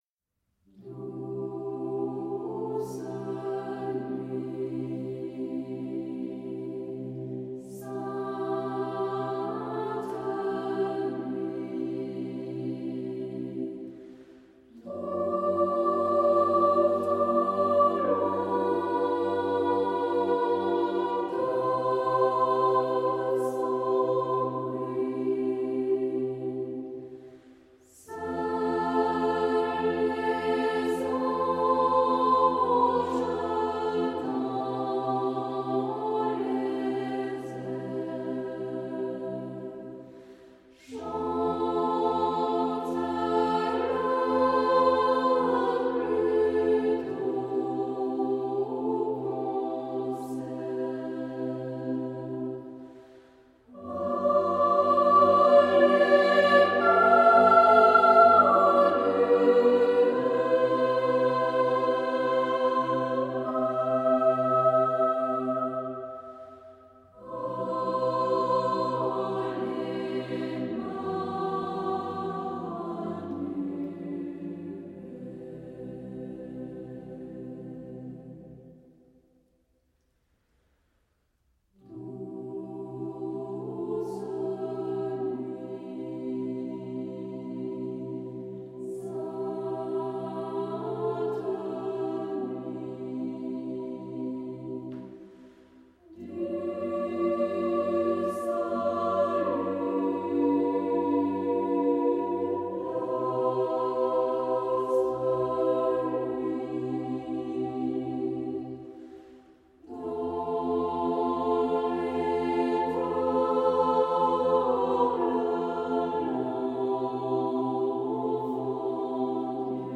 R�p�tition de la pi�ce musicale N